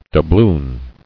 [dou·bloon]